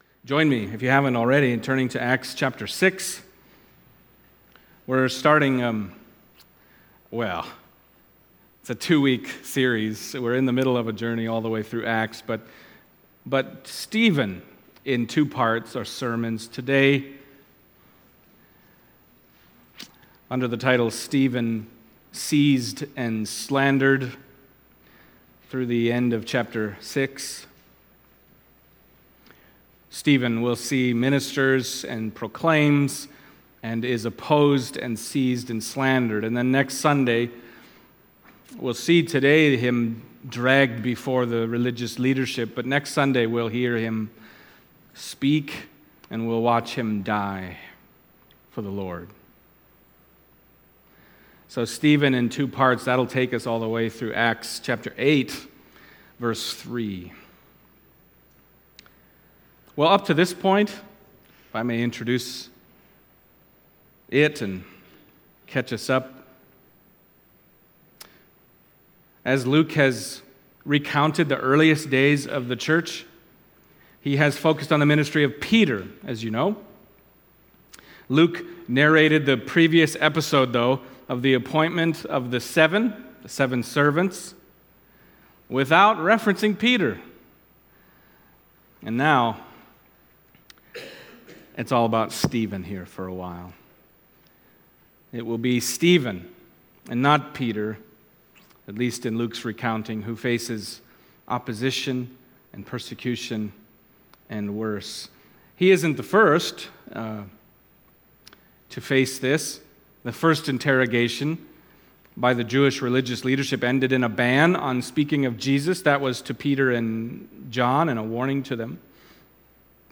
Acts Passage: Acts 6:8-15 Service Type: Sunday Morning Acts 6:8-15 « Division of Labor in the Early Church Stephen